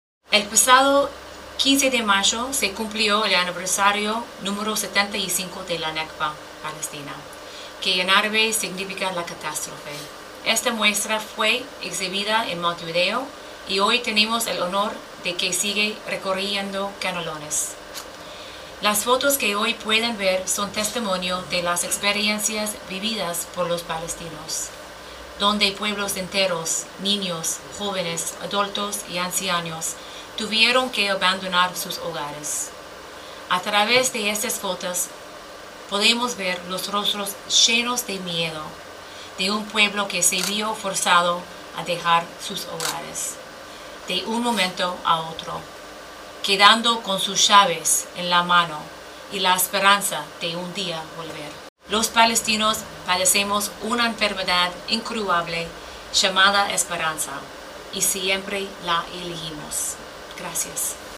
Embajadora del Estado de Palestina en Uruguay, Nadya Rasheed, en su oratoria